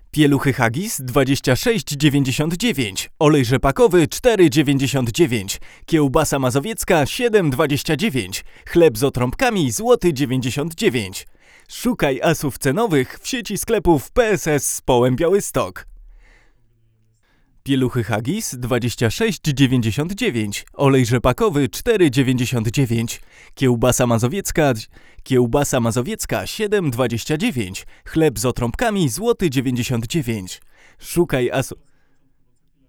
Do testów używamy popularnego Neumanna TLM 103, i dla równowagi U89 tego samego producenta.
Brzmienie tego przedwzmacniacza naprawdę nie pozostawia wiele do życzenia.